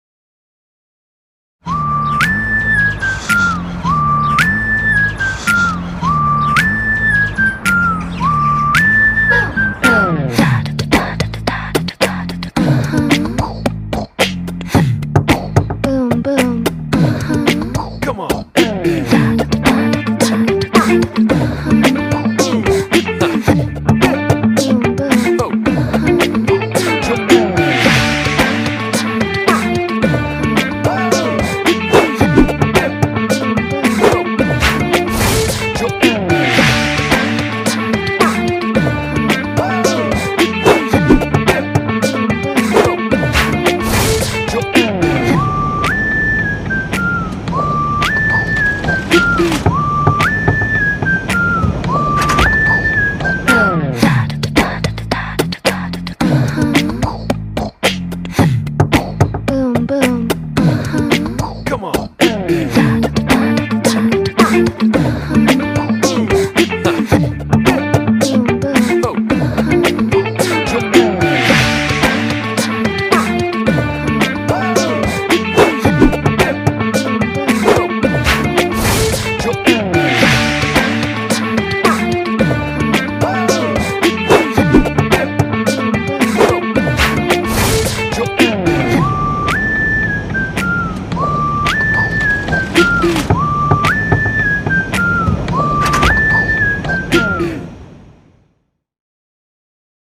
tema dizi müziği, heyecan eğlenceli enerjik fon müziği.